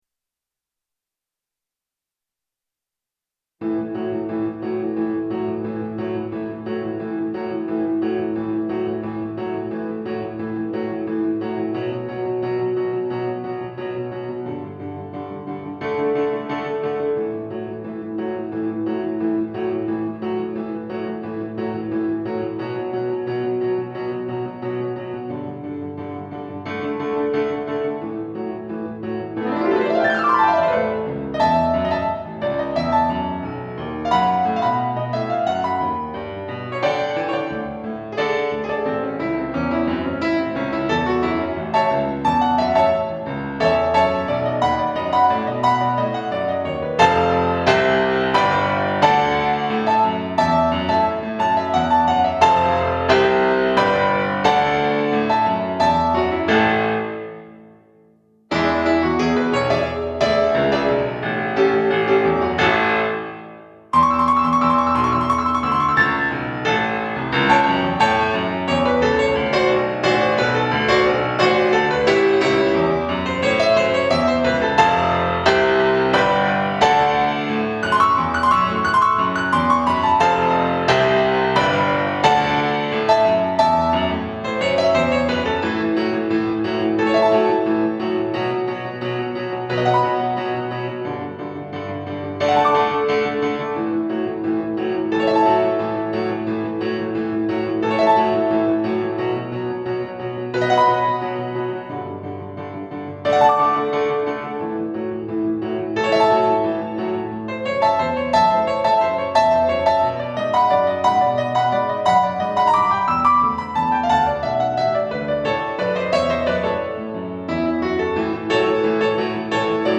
Time-Warp-Backing.mp3